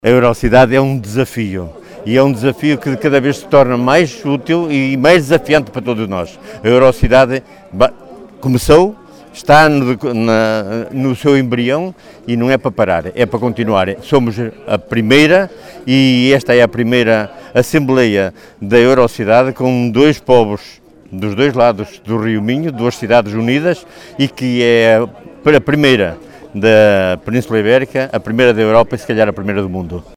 “A Eurocidade é um desafio que se torna cada vez mais útil para todos nós”, considerou o presidente da Câmara de Valença, Manuel Lopes à Rádio Vale do Minho, realçando a partilha de equipamentos de vária índole que já tem sido feita pelas duas cidades.